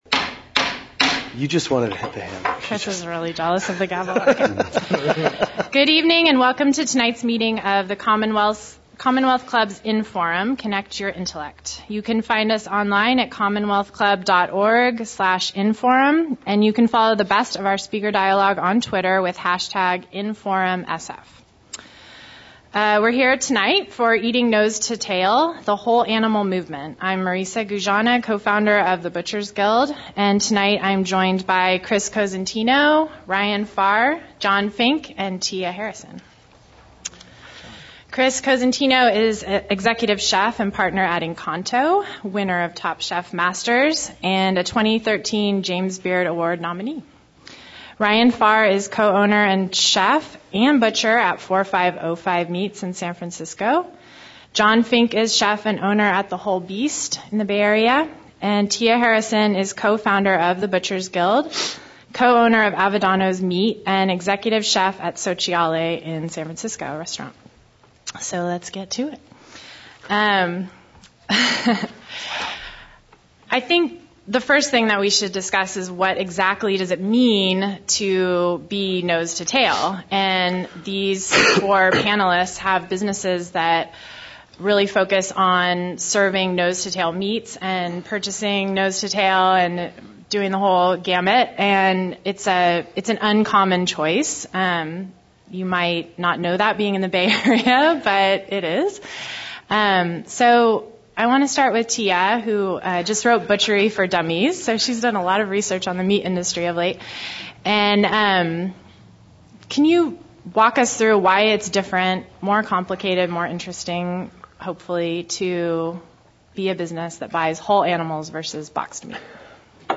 Premium tickets are sold out but General Admission tickets to the panel discussion are still available.